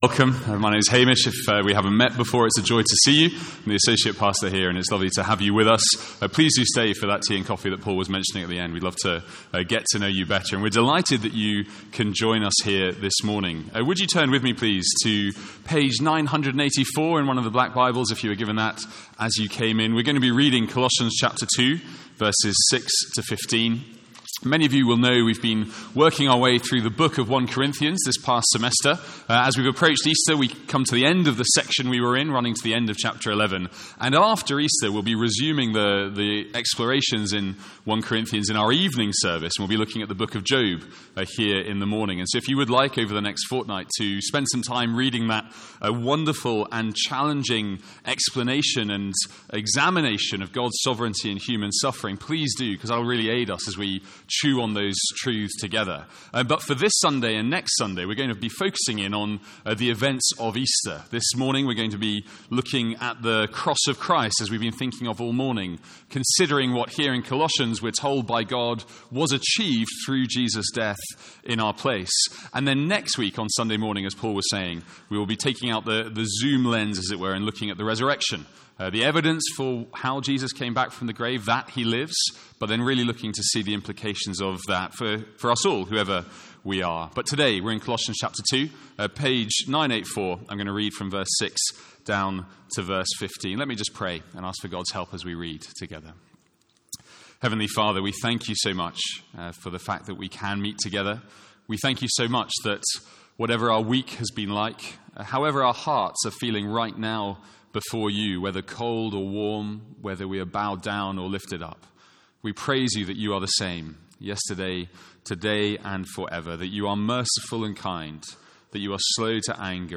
From our morning Giving Sunday service.